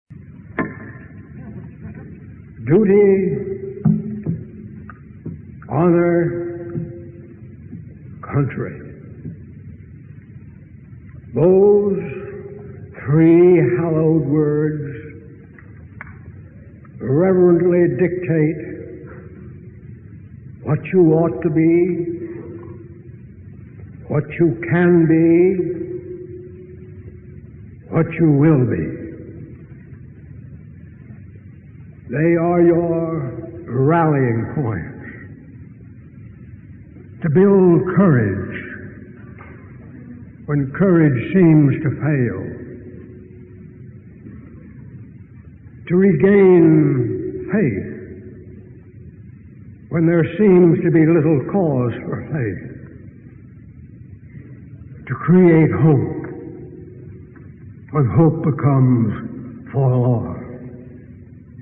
Tags: Travel Asyndeton Figure of Speech Brachylogia Speeches